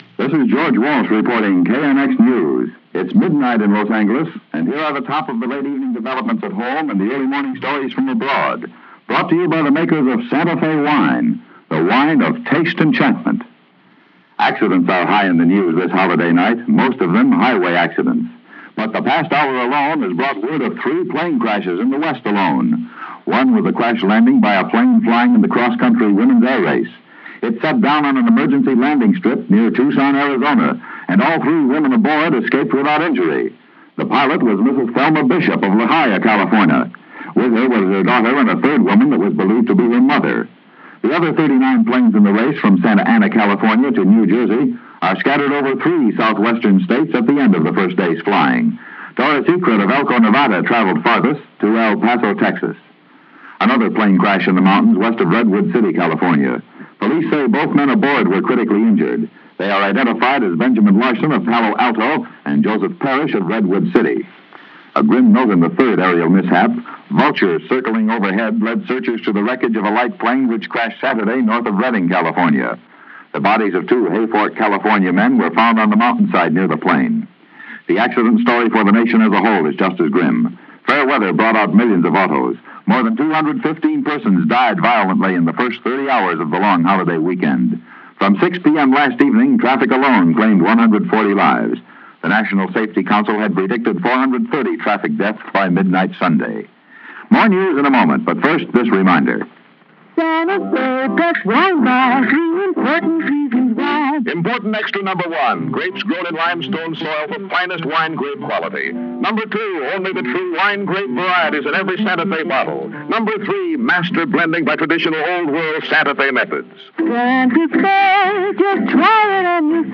This is L.A. at midnight – July 4th is turning into July 5th and the news recaps the goings on of the day.